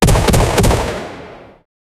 Add brief echo and subtle reverb for realism, emphasizing swift, precise contact. 0:10 Sequência de Tiros de canhão pesado consecutivos com graves e médios 0:02
sequncia-de-tiros-de-canh-btbwl3wp.wav